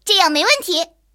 野牛强化语音.OGG